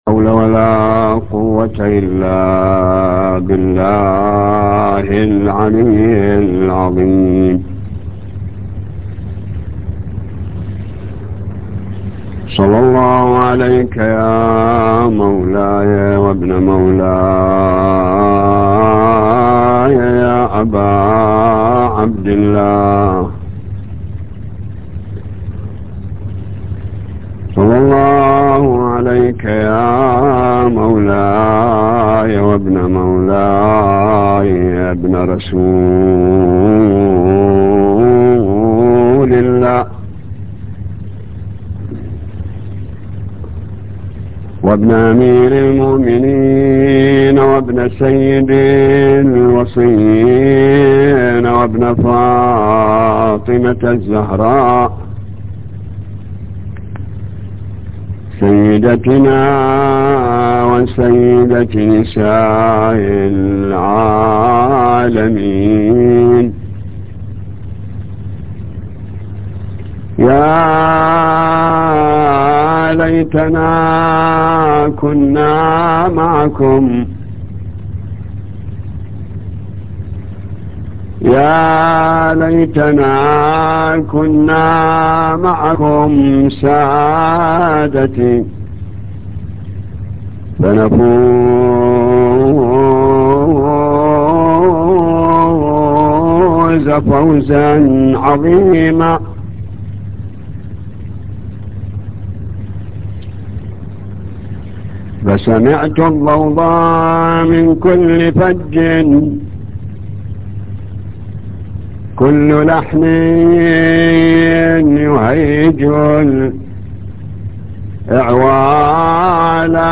نواعي حسينية 2